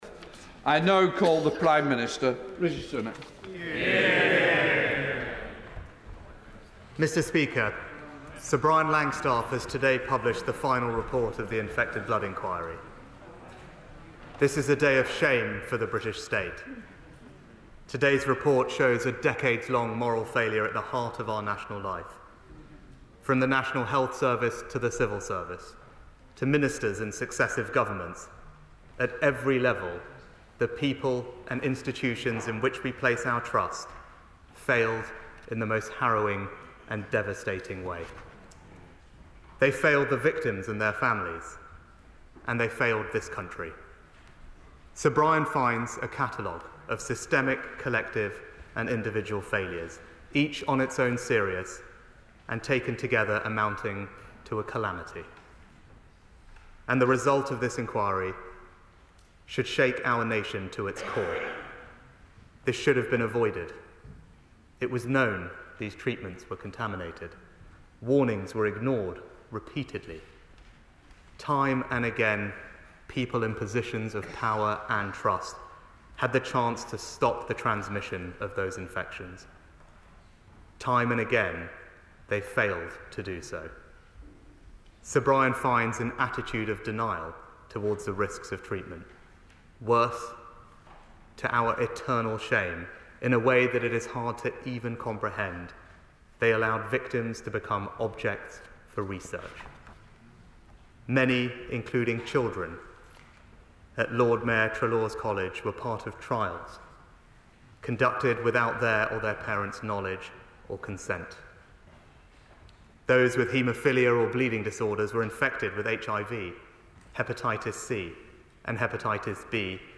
Rishi Sunak Statement to the House of Commons on the UK Contaminated Blood Inquiry Findings (transcript-audio-video)